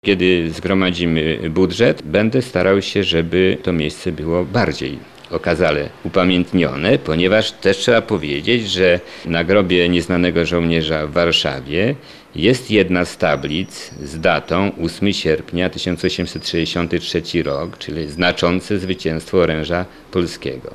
– Jeśli uda się zebrać pieniądze, być może stanie nowy monument – mówi wójt gminy Żyrzyn, Andrzej Bujek.